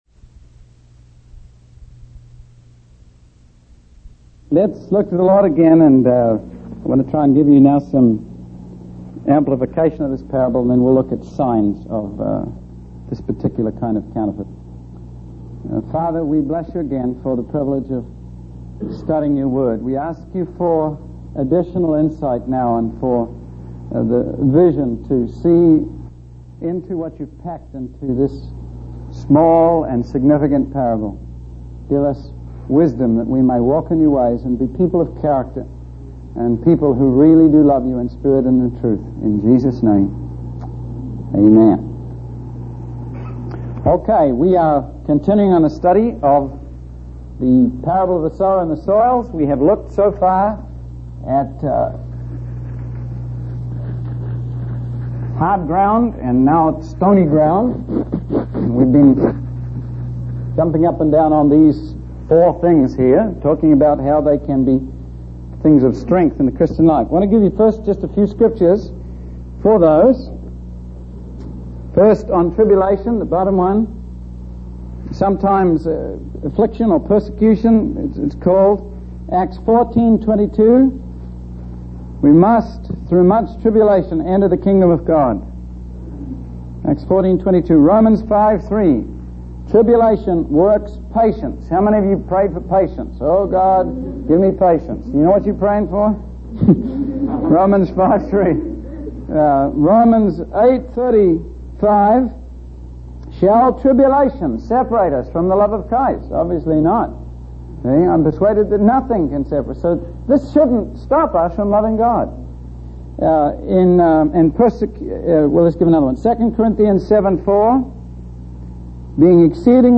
In this sermon, the speaker talks about a morning prayer meeting where many high school students gathered to pray.